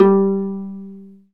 Index of /90_sSampleCDs/Roland - String Master Series/STR_Viola Solo/STR_Vla Pizz